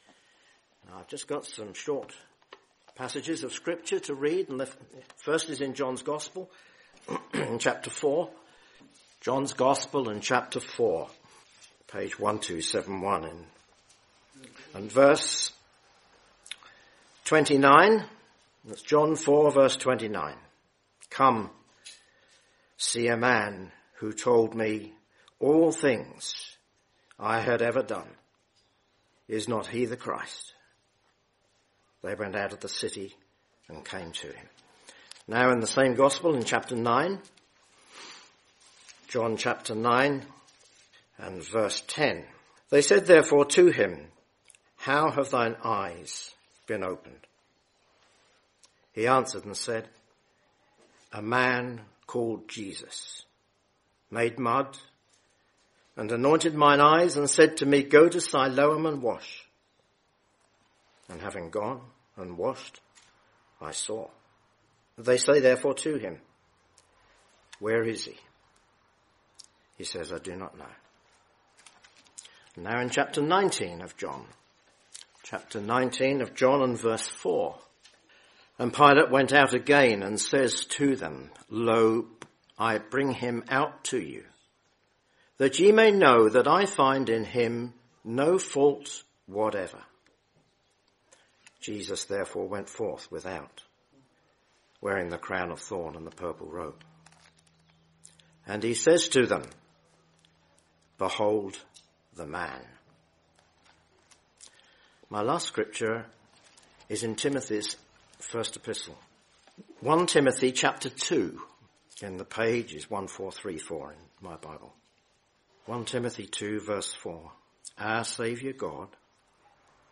In this Gospel preaching you will hear about God's son, a man called Jesus who came into this world to bring Salvation to mankind.